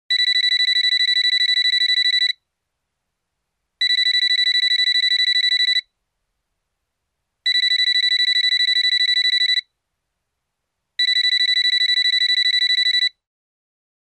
Звуки звонящего телефона